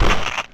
cop_splat.ogg